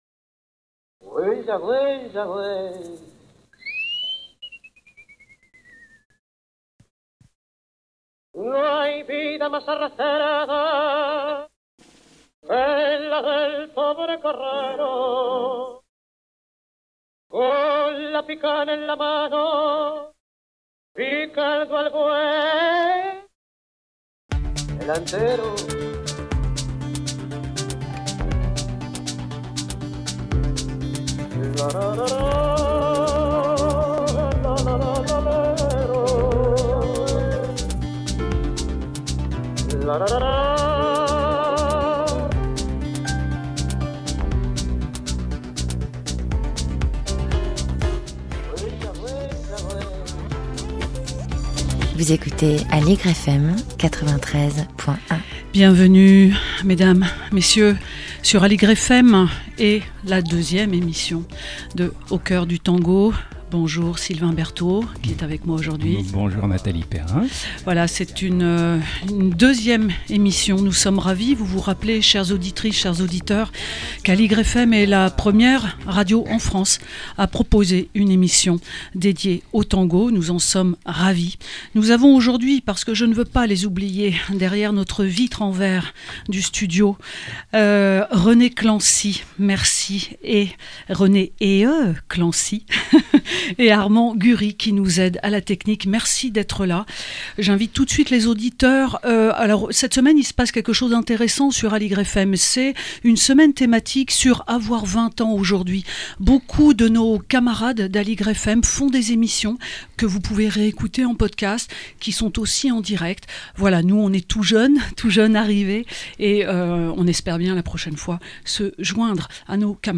Les illustrations musicales choisies par les co-auteurs rythment l'émission.